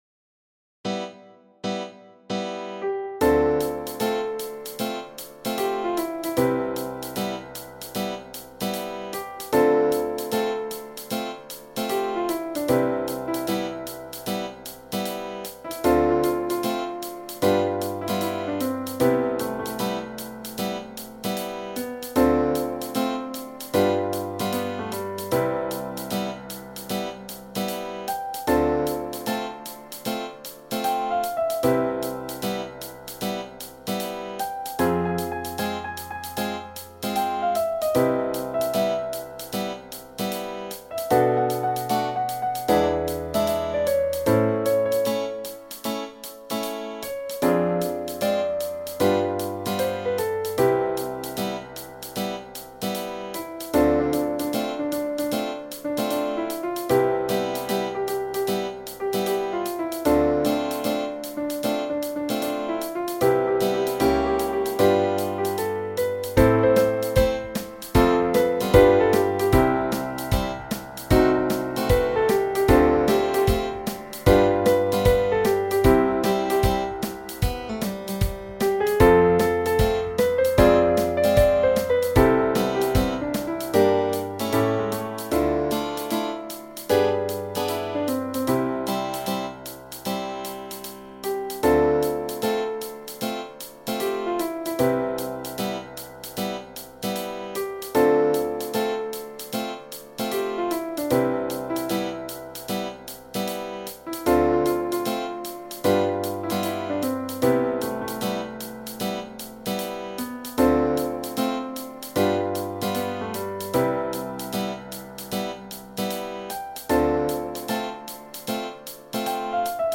Genere: Jazz